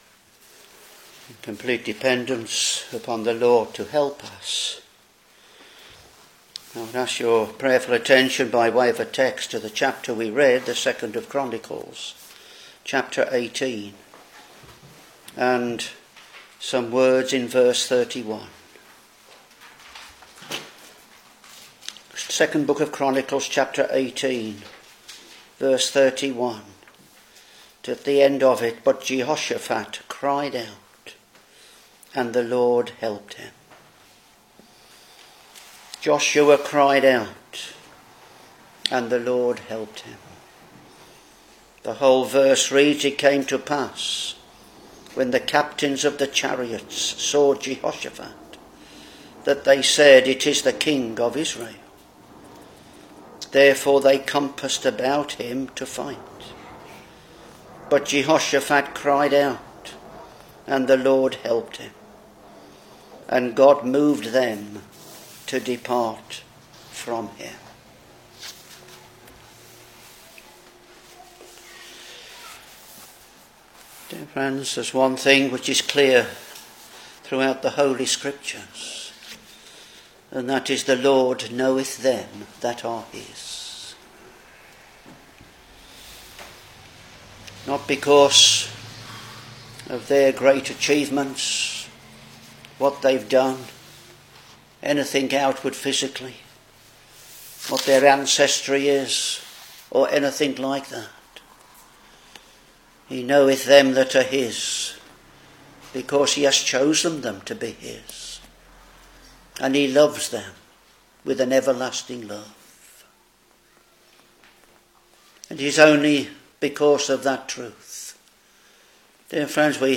Sunday (PM) 27-JUL-2025: preached